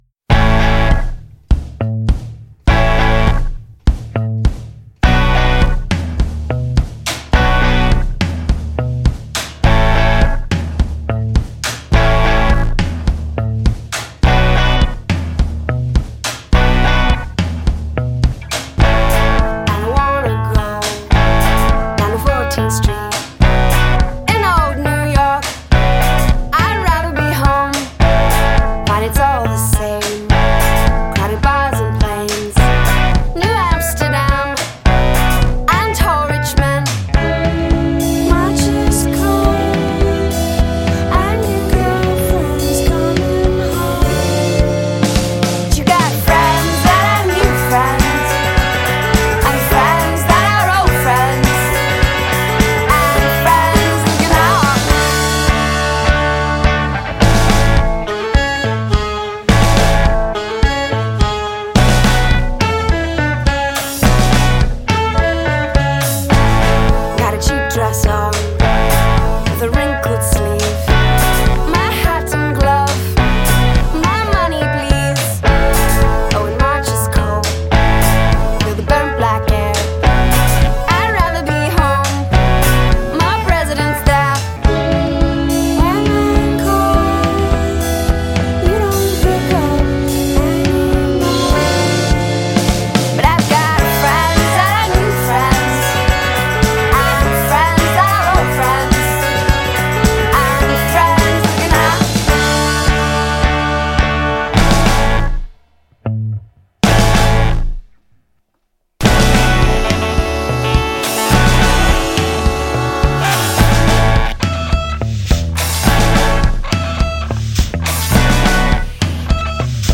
indie pop band